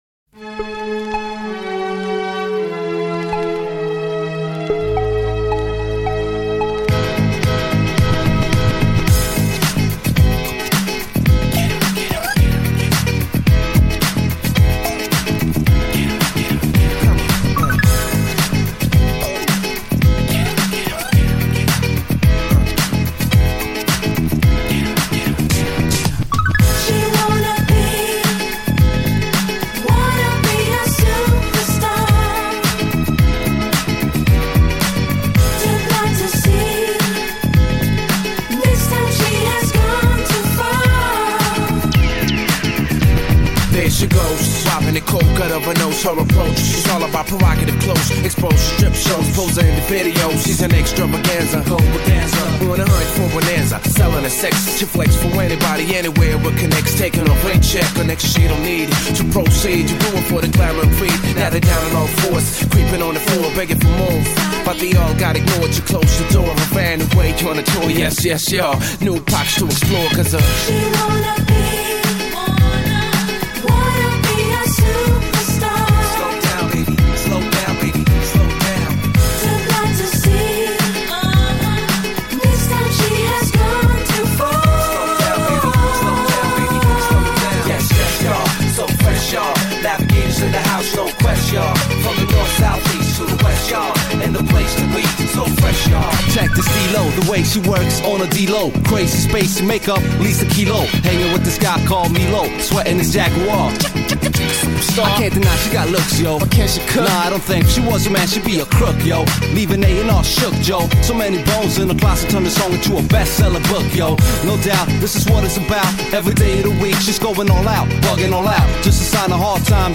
Жанр: Rap/Hip Hop